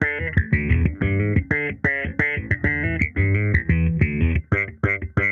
Index of /musicradar/sampled-funk-soul-samples/90bpm/Bass
SSF_JBassProc1_90E.wav